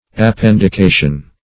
Appendication \Ap*pend`i*ca"tion\, n.